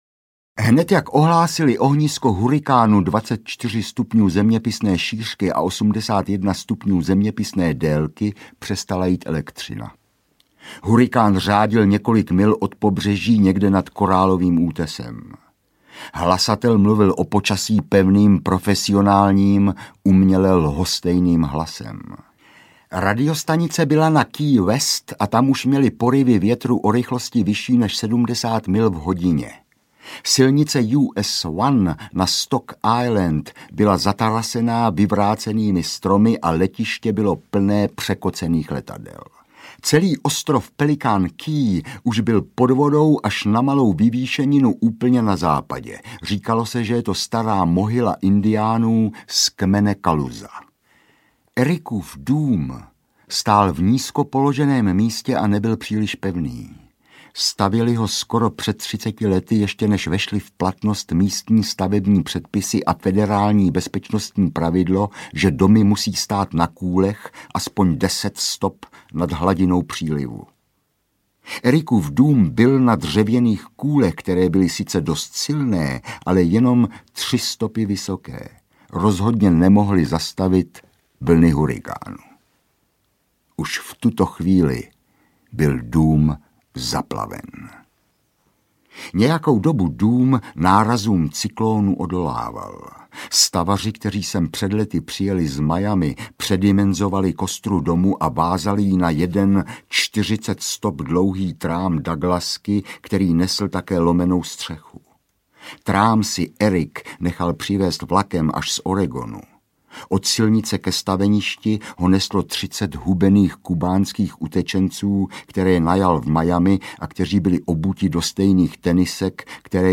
Audiokniha Hurikán a jiné povídky, kterou napsal Jarda Červenka.
Ukázka z knihy
• InterpretJan Tříska